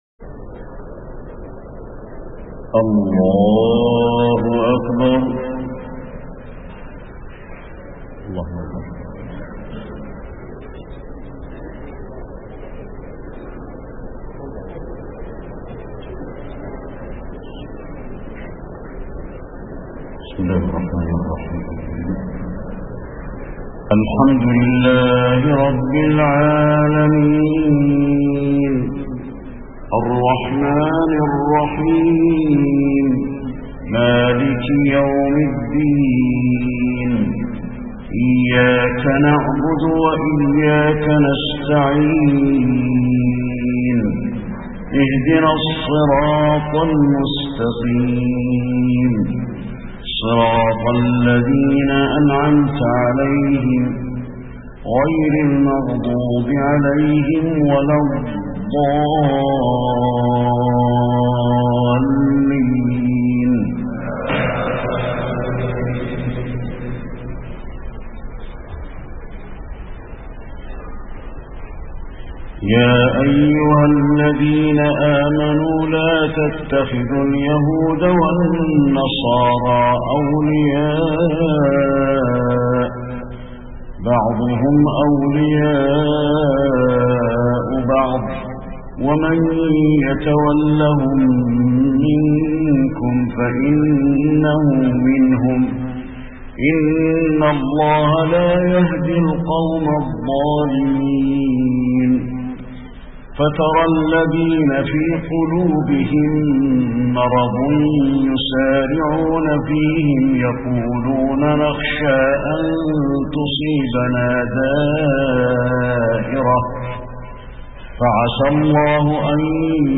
تهجد ليلة 25 رمضان 1432هـ من سورة المائدة (51-108) Tahajjud 25 st night Ramadan 1432H from Surah AlMa'idah > تراويح الحرم النبوي عام 1432 🕌 > التراويح - تلاوات الحرمين